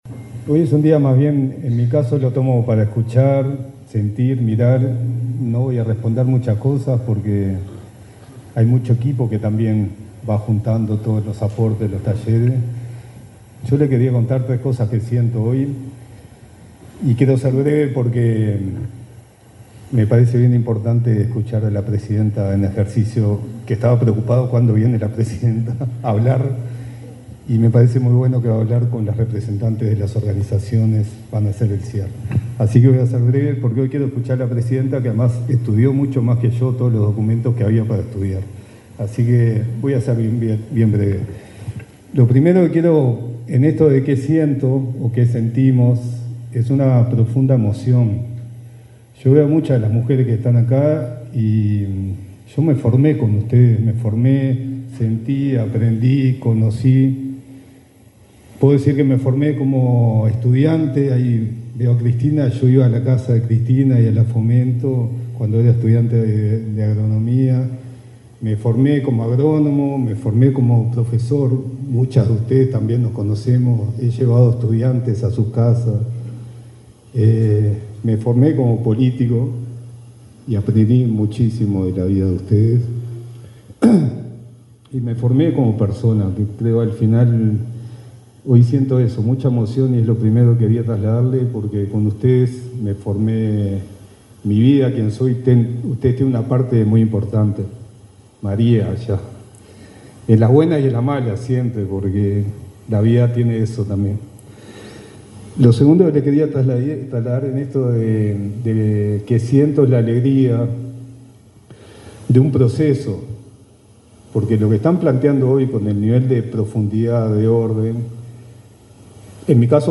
El subsecretario de Ganadería, Matías Carámbula, y la directora del Instituto Nacional de las Mujeres, Mónica Xavier, se expresaron durante el
Palabra de autoridades en encuentro de mujeres rurales 15/10/2025 Compartir Facebook X Copiar enlace WhatsApp LinkedIn El subsecretario de Ganadería, Matías Carámbula, y la directora del Instituto Nacional de las Mujeres, Mónica Xavier, se expresaron durante el encuentro nacional: 10 años del Espacio de Diálogo de Mujeres Rurales, realizado este miércoles 15 en Florida.